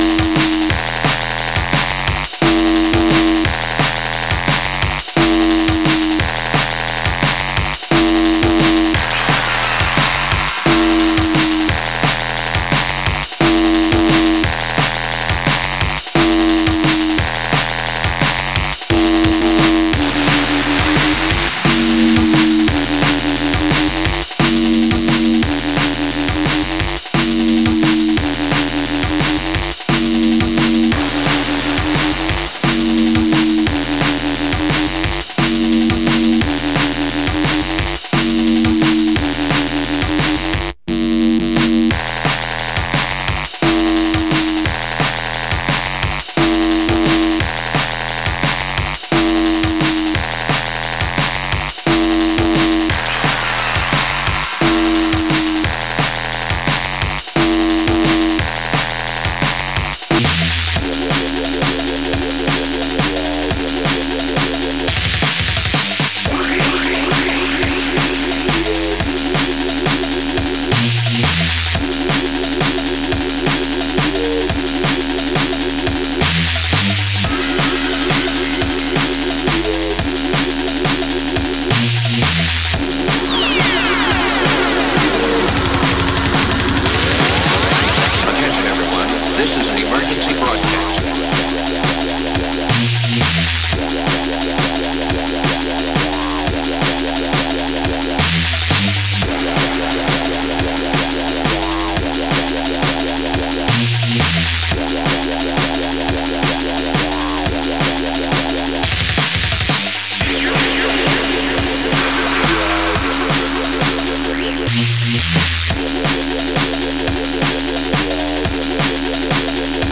Drum N Bass